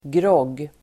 Ladda ner uttalet
grogg substantiv, drink made by mixing spirits with a soft drink Uttal: [gråg:] Böjningar: groggen, groggar Definition: dryck av sprit blandad med läskedryck Sammansättningar: grogg|virke (mixer), whiskygrogg (whisky and soda)